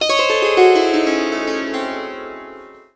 SITAR LINE10.wav